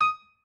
pianoadrib1_62.ogg